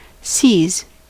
Ääntäminen
Ääntäminen : IPA : /siːz/ US : IPA : [siːz] Haettu sana löytyi näillä lähdekielillä: englanti Käännöksiä ei löytynyt valitulle kohdekielelle.